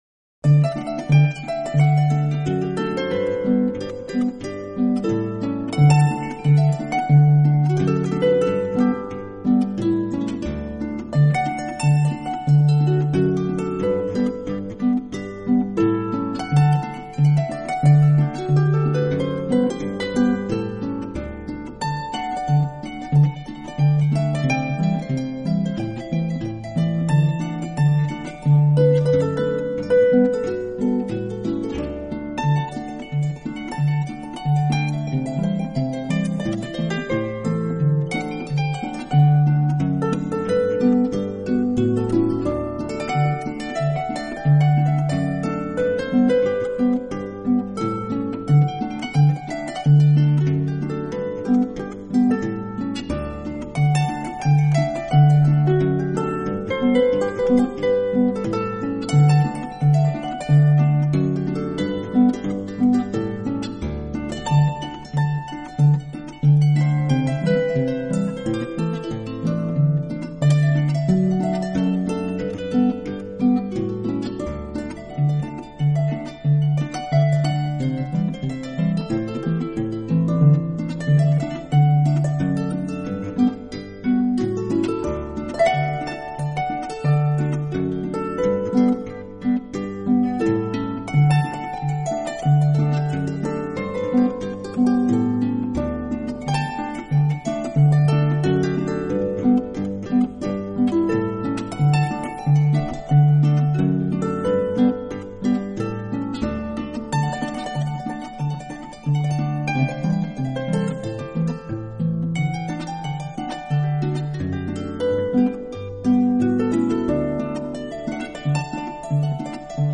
音乐风格：Celtic, Scottish, Folk
压缩码率：320kbps 44100Hz Stereo